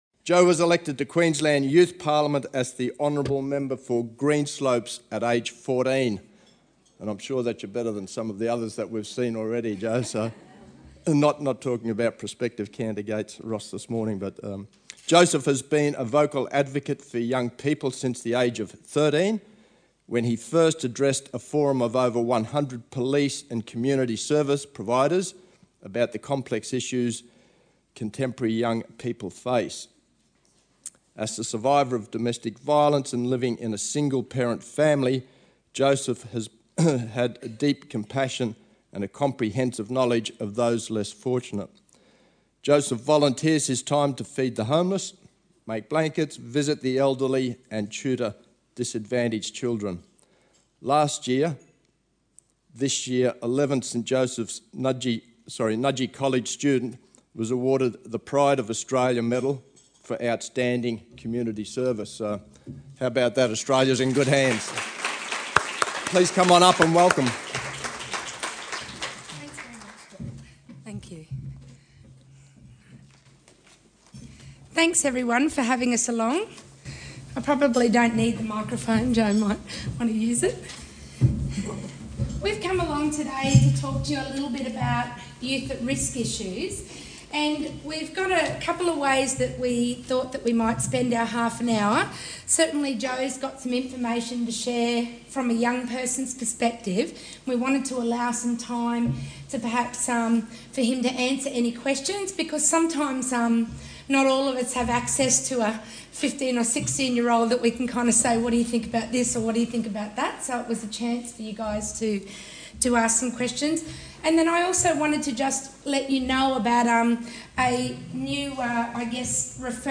Conference Audio Recordings
PFLAG held its third national conference in 2007 at the Brisbane Convention & Exhibition Centre. A number of guest speakers took part – and you can find audio recordings of their speeches below.